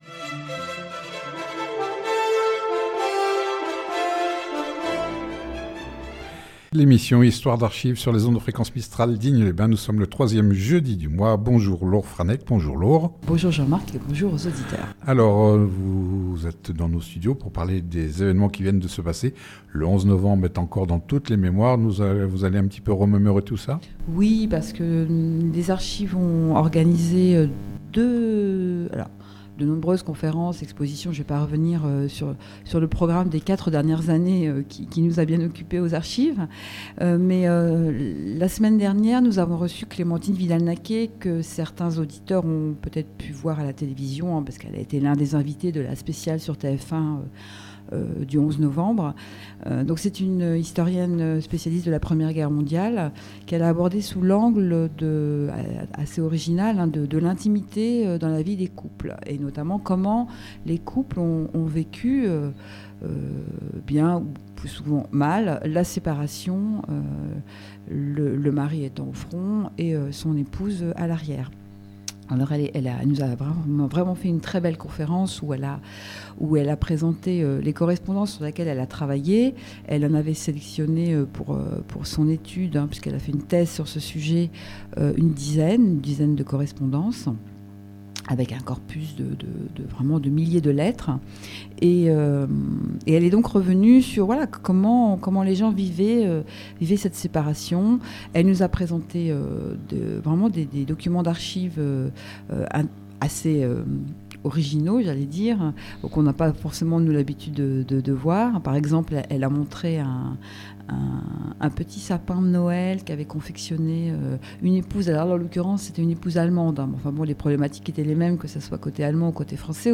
Cette émission sur fréquence mistral à Digne, a lieu tous les 3èmes jeudi du mois en direct de 9h10 à 10h